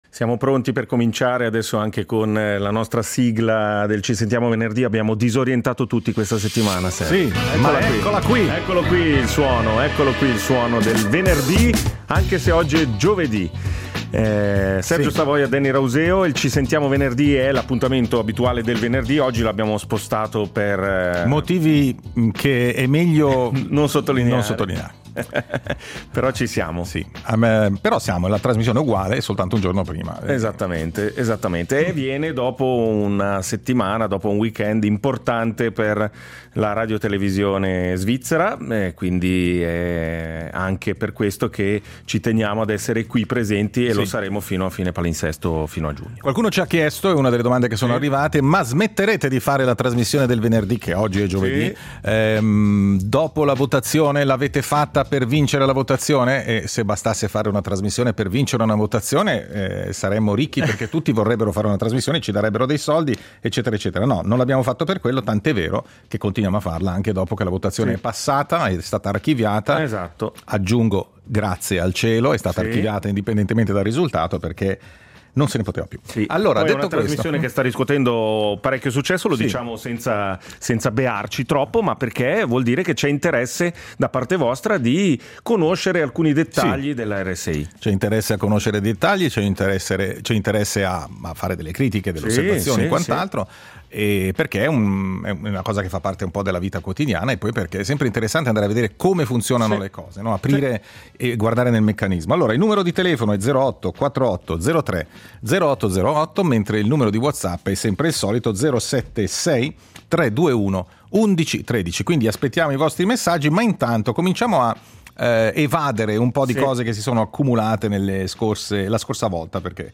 Il tavolo radiofonico dove ci si parla e ci si ascolta.